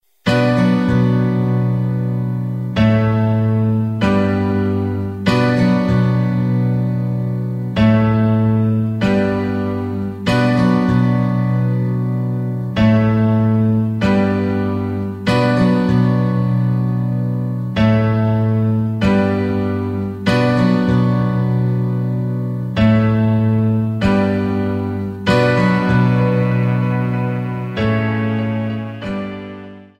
Backing track files: Rock (2136)
Buy With Backing Vocals.